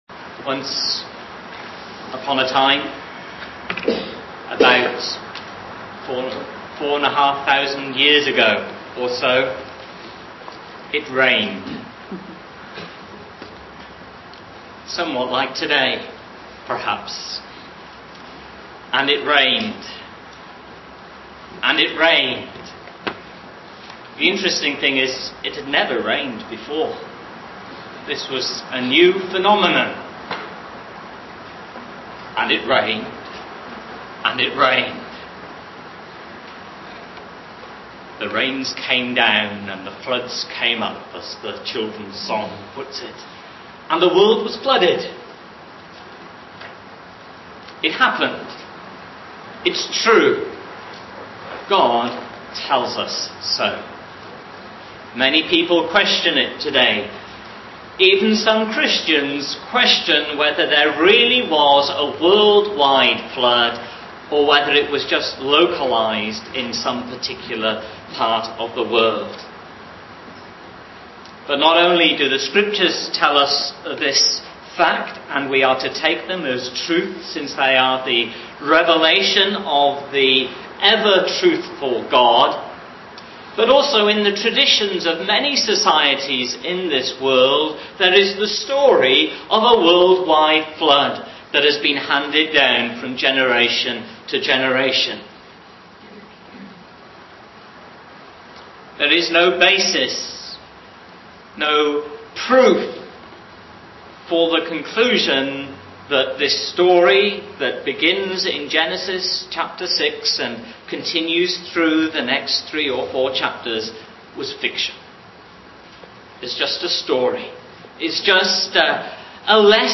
GBC Sermon Archive